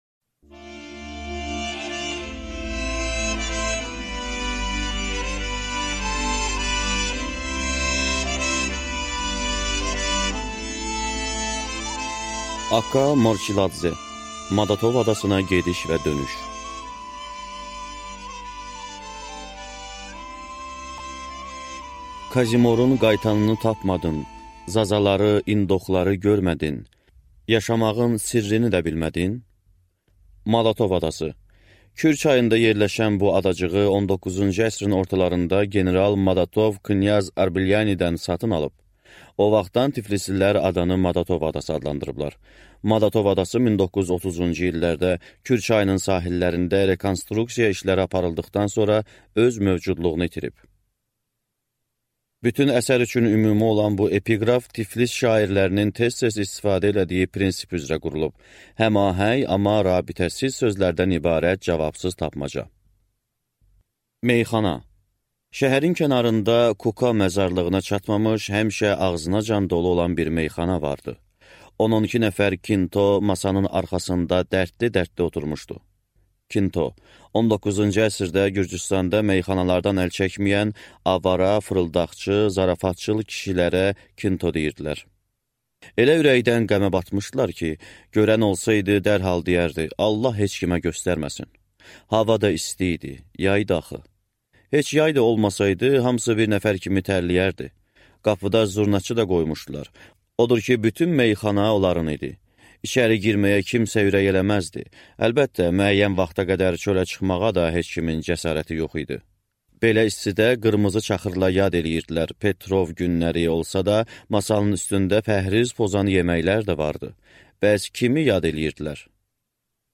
Аудиокнига Madatov adasına gediş və dönüş | Библиотека аудиокниг